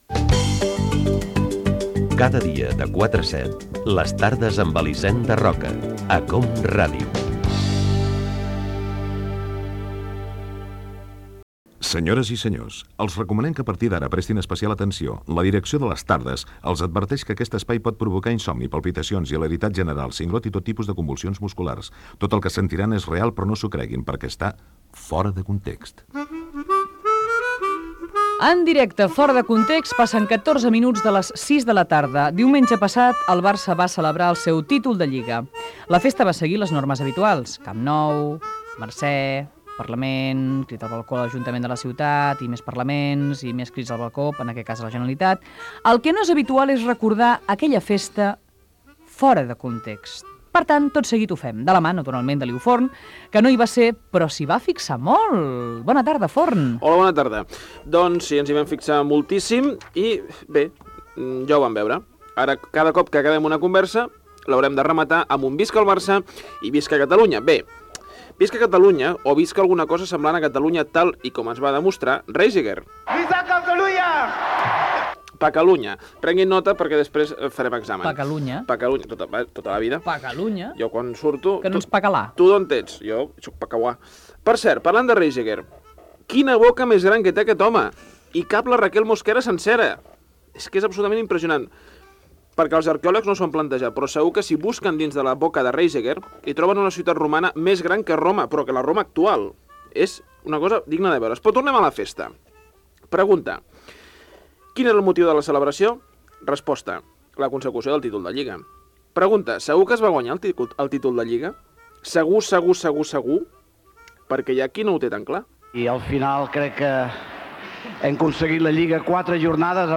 espai humorístic dedicat a la celebració del campionat de lliga del F.C. Barcelona
Gènere radiofònic Entreteniment